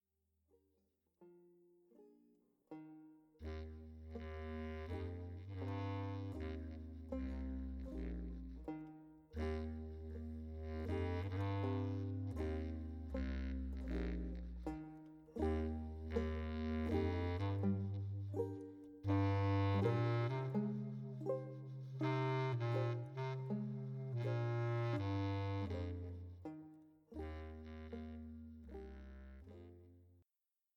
超絶技巧を駆使しあらゆる時代を駆け巡る”究極のデュオ”、
Clarinet & Bass Clarinet &
Curved Soprano Saxophone
Banjo